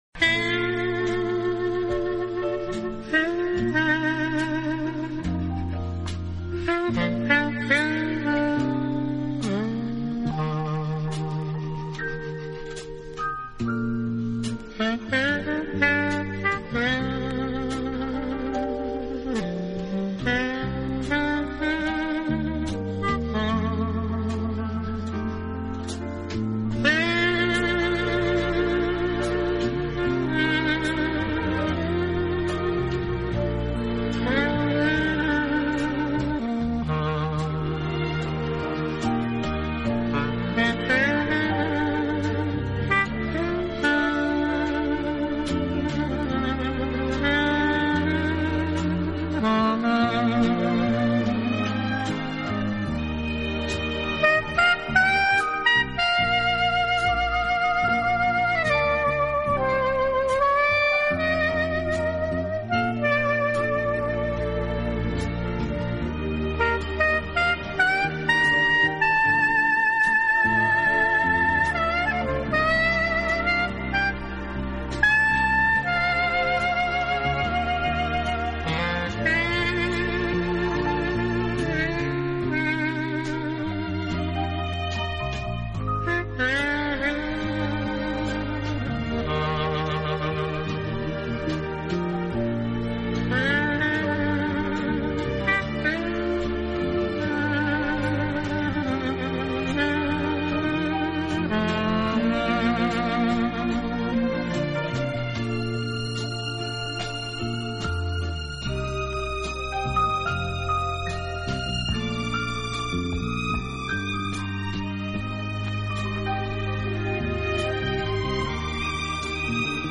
【黑管专辑】
风格：POP/JAZZ/OTHER
黑管那种明亮而又有光泽的音色、幽美而缠绵的旋律，无论是在什么样的心情下